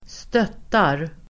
Uttal: [²st'öt:ar]